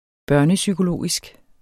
Udtale [ -sygoˌloˀisg ]